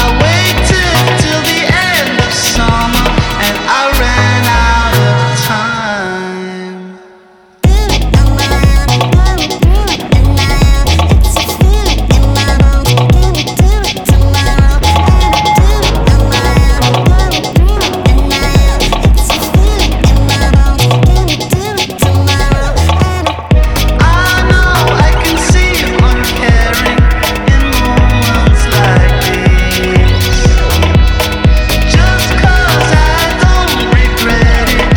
Жанр: Танцевальные / Альтернатива